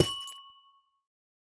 Amethyst_break3.ogg.mp3